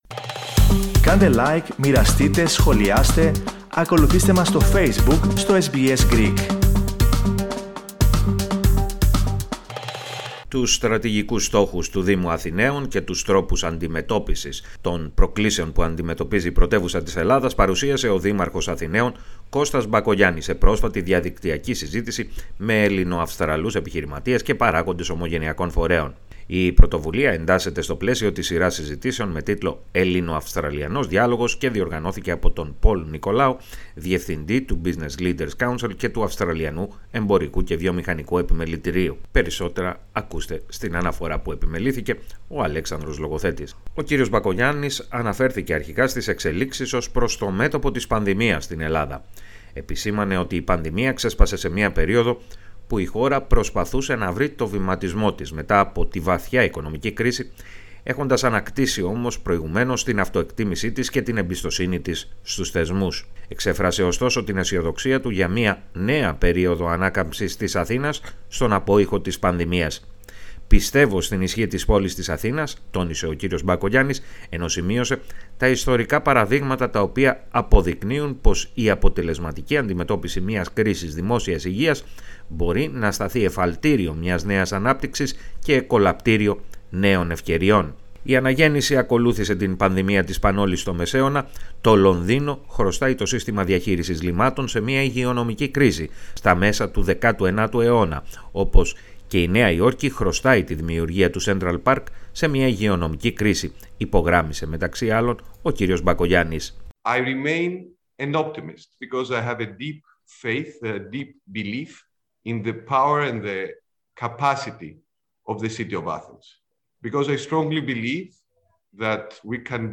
Τους στρατηγικούς στόχους του Δήμου Αθηναίων και τους τρόπους αντιμετώπισης των προκλήσεων που αντιμετωπίζει η πρωτεύουσα της Ελλάδας, παρουσίασε ο δήμαρχος Αθηναίων, Κώστας Μπακογιάννης σε πρόσφατη διαδικτυακή συζήτηση με Ελληνοαυστραλούς επιχειρηματίες και παράγοντες ομογενειακών φορέων.